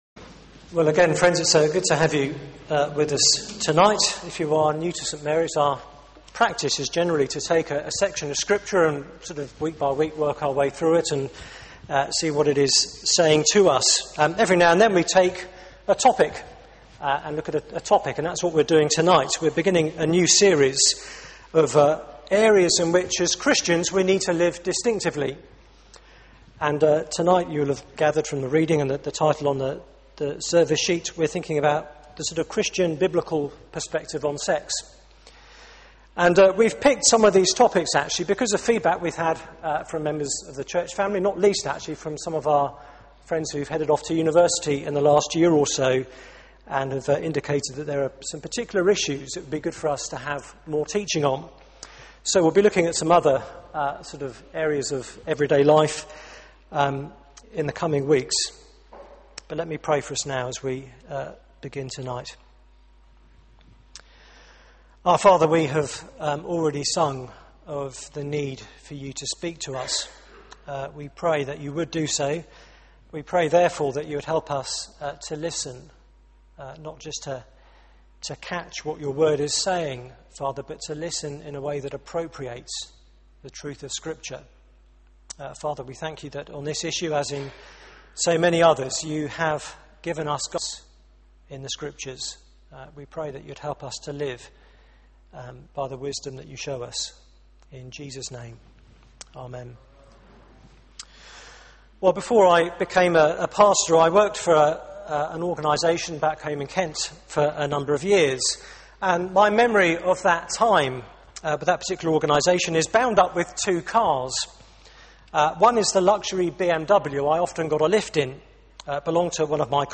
Media for 6:30pm Service on Sun 04th Sep 2011 18:30 Speaker
Theme: A distinctive view of sex Sermon